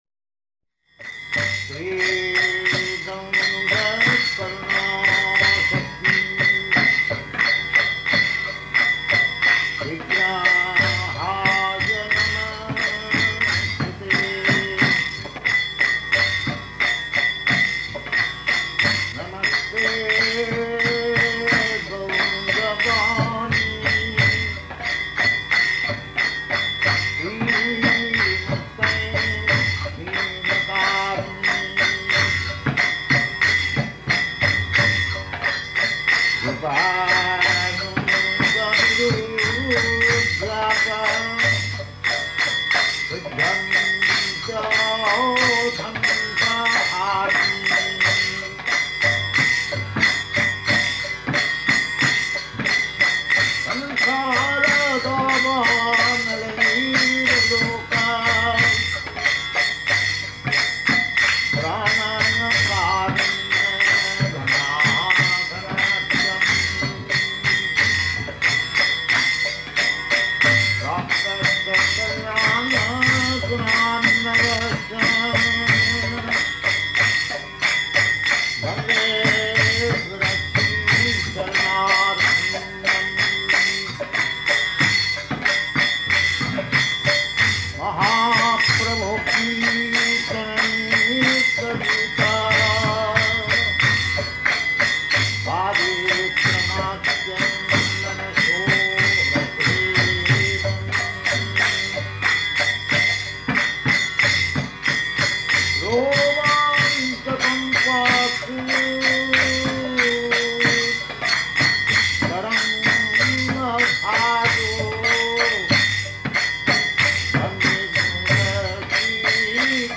Mangala Arati - ISKCON Mayapur